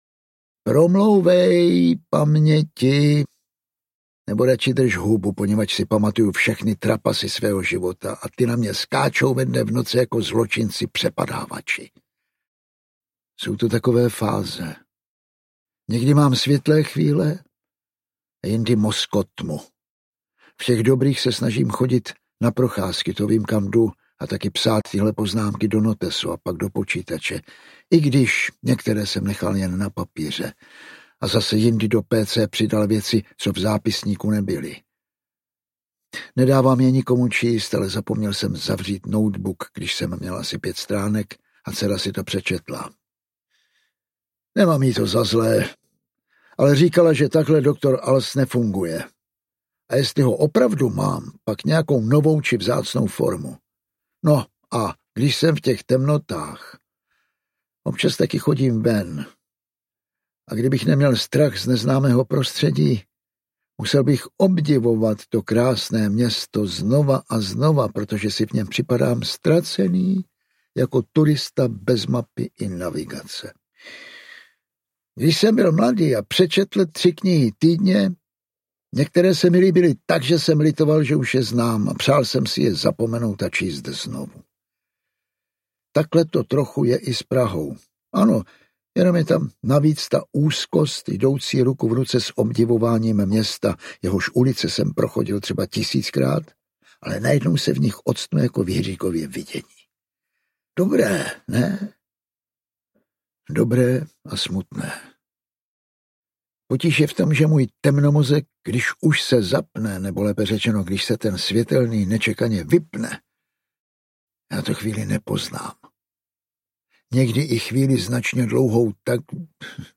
Dr. Alz audiokniha
Ukázka z knihy
Čtou Jan Vlasák, Vasil Fridrich.
Vyrobilo studio Soundguru.
• InterpretJan Vlasák, Vasil Fridrich